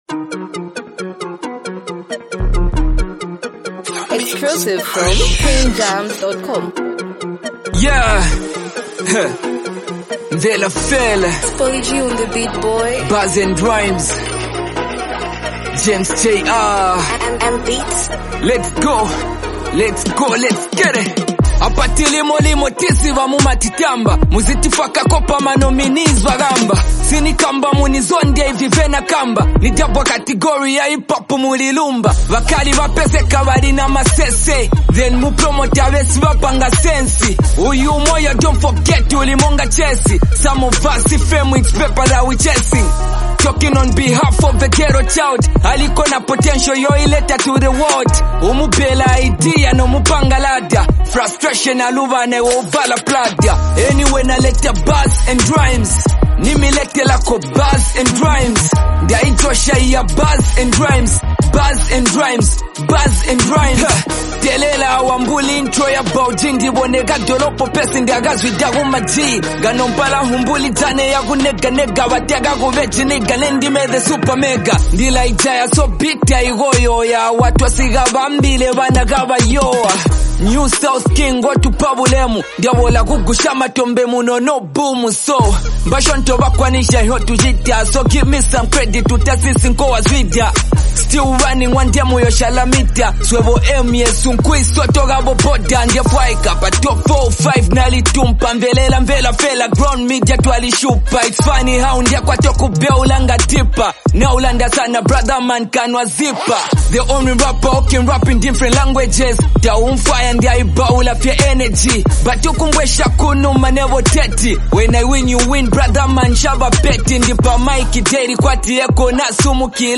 For fans of raw rap and authentic expression